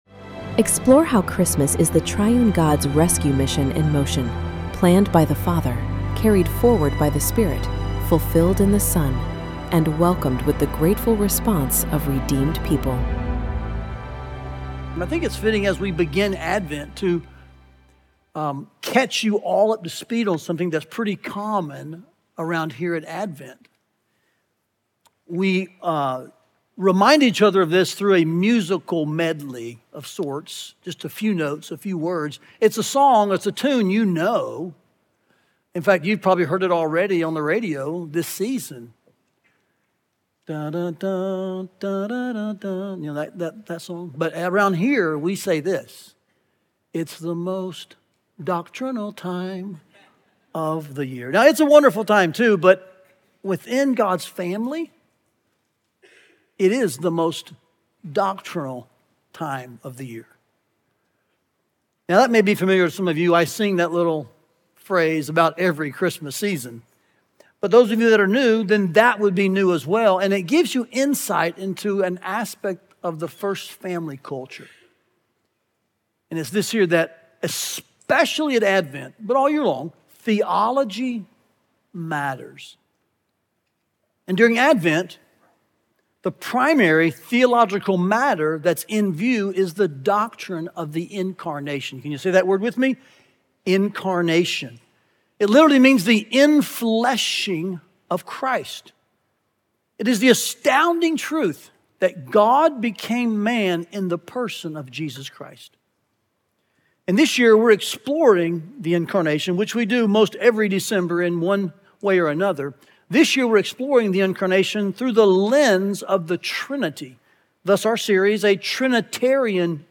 Listen to the first sermon from Advent 2025 and learn more about our Advent series here.